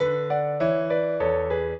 piano
minuet6-8.wav